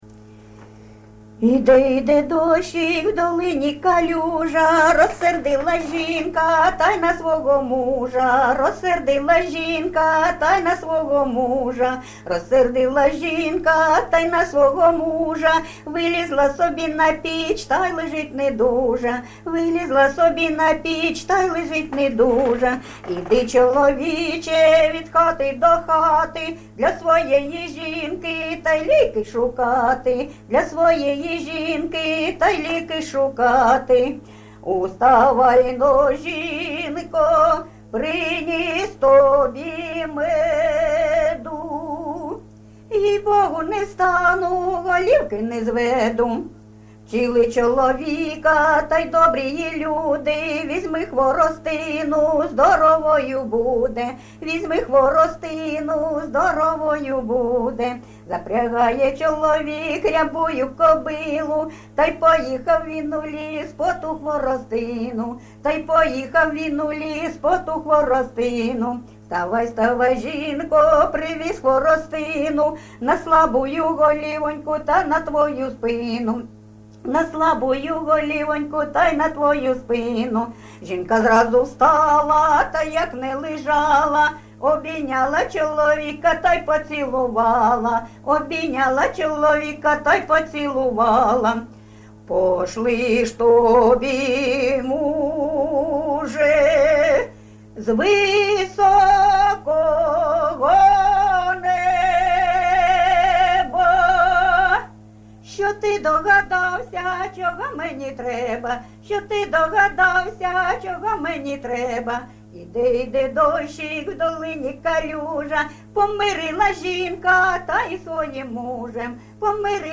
ЖанрПісні з особистого та родинного життя, Жартівливі
Місце записум. Часів Яр, Артемівський (Бахмутський) район, Донецька обл., Україна, Слобожанщина